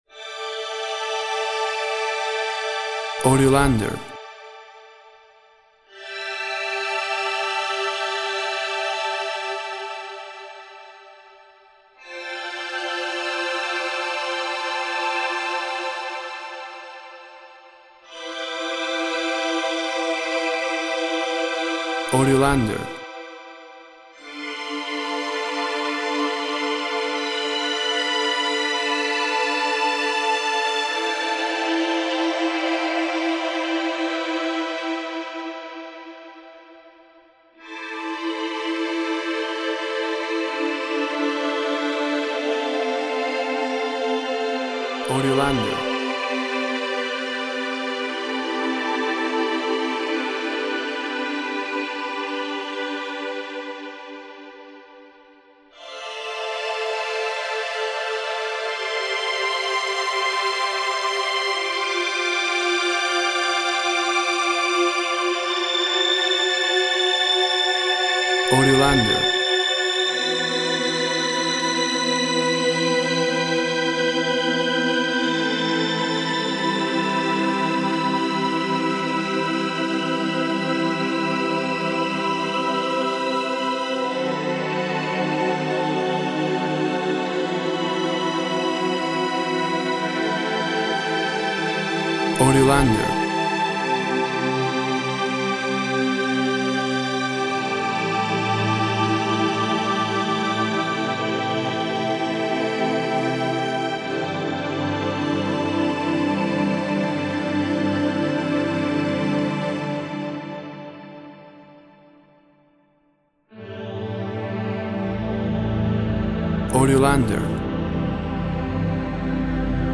String orchestra plays lush night music.
Tempo (BPM) 40